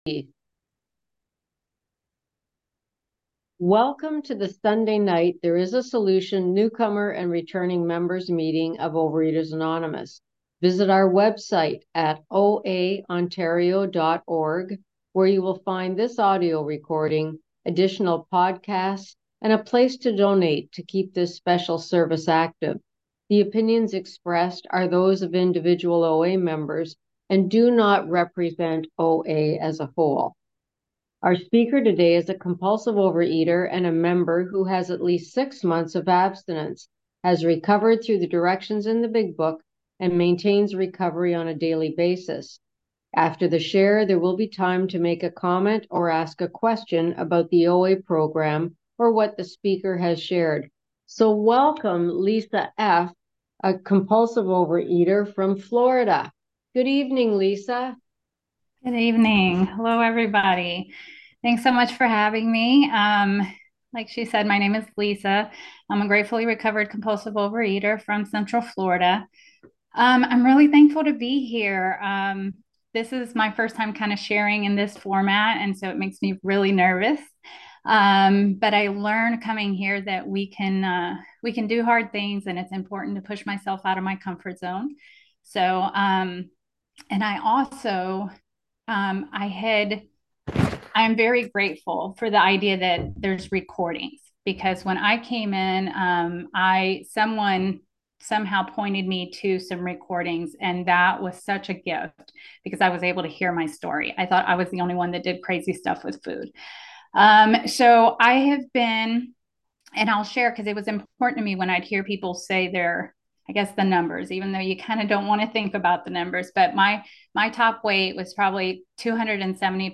OA Newcomer Meeting